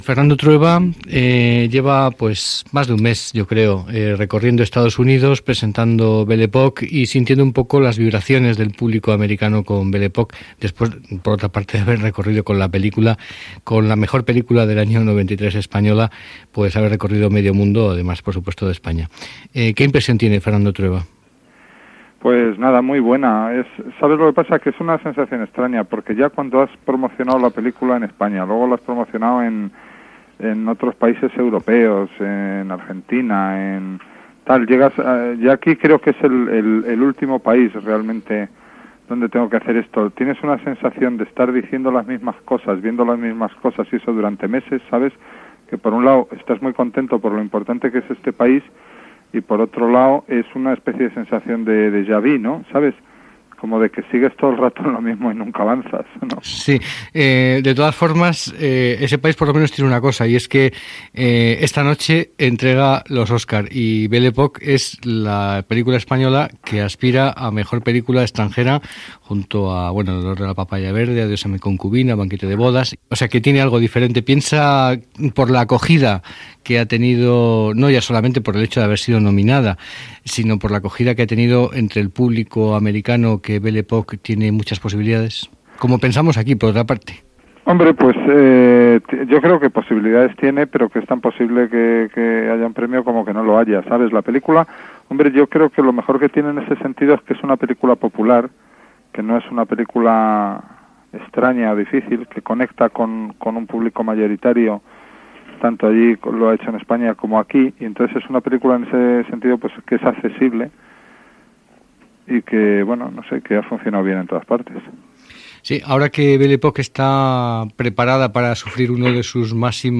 Entrevista telefònica al director de cinema Fernando Trueba, que està a Los Ángeles, hores abans del lliurament dels premis Oscars.